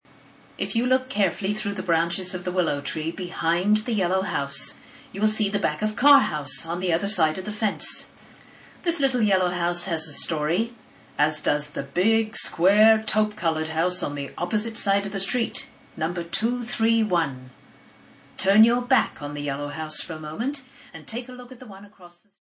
Type: iPod/MP3 Audio Tours
All information has been carefully researched for accuracy and is presented by professional narrators.